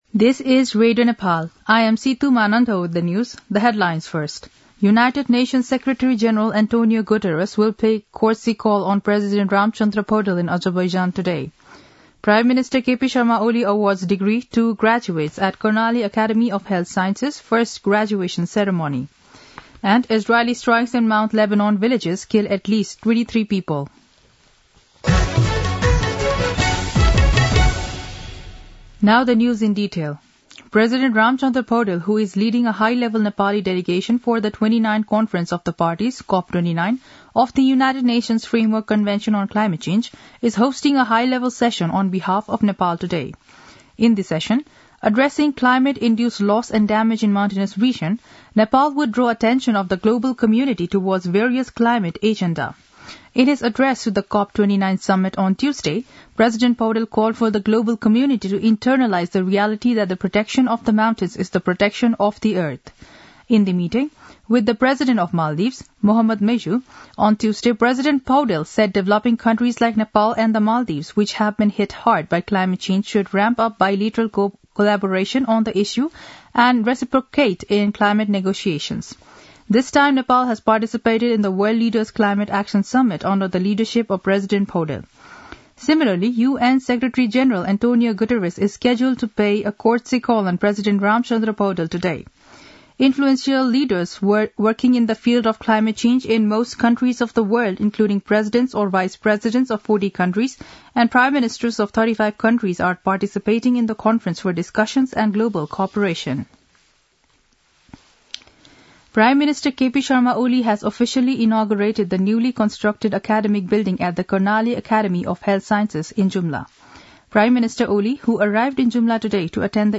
दिउँसो २ बजेको अङ्ग्रेजी समाचार : २९ कार्तिक , २०८१
2-pm-english-news-1-3.mp3